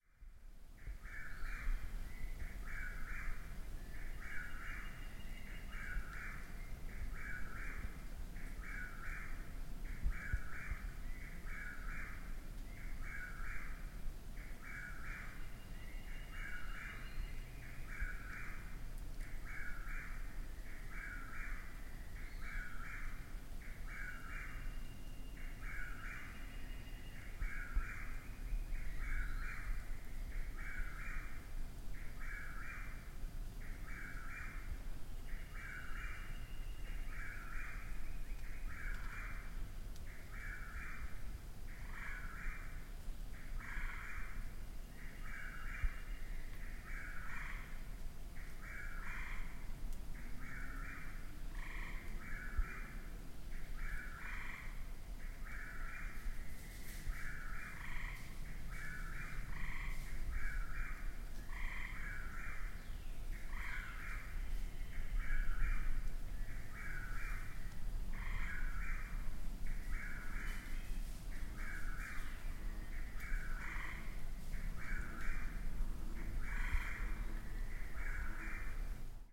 Chuck-will’s-widow – from Glen Wild Lake, Bloomingdale, NJ, 20:45, 6/8/2011.
Here is a brief recording, albeit distant and noisy, with a Northern Gray Treefrog, a Fowler’s Toad (if you listen closely enough) and other birds in the background. This is the Chuck-will’s-widow population that has been at this locale for over ten years.